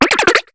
Cri de Lépidonille dans Pokémon HOME.